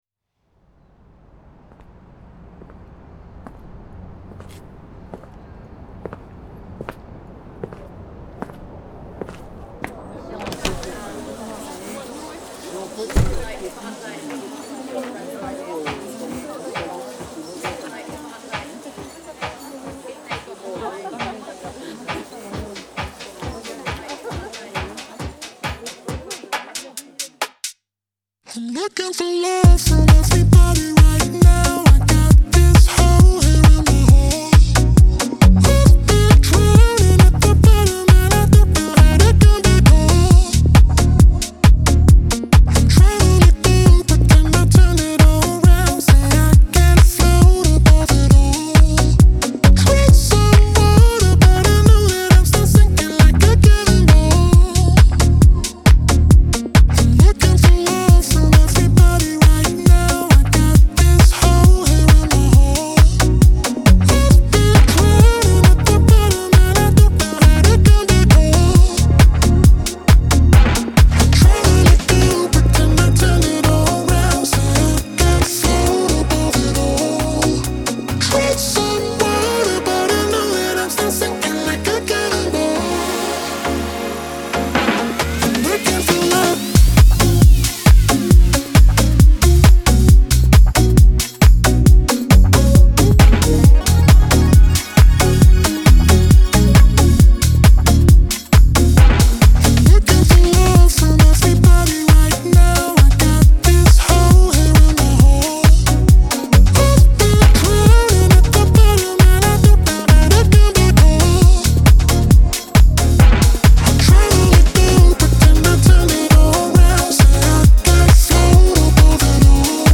Genre : Electronic